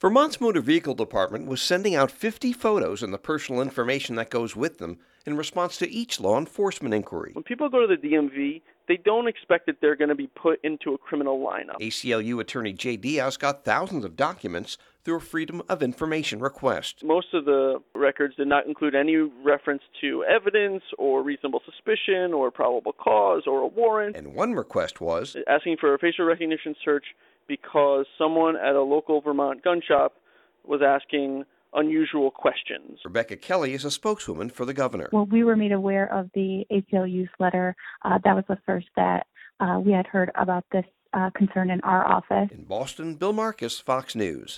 (BOSTON) MAY 26 – VERMONT’S GOVERNOR IS SUSPENDING A DEPARTMENT OF MOTOR VEHICLE FACIAL RECOGNITION PROGRAM PENDING A REVIEW BY THE STATE’S ATTORNEY GENERAL. FOX NEWS RADIO’S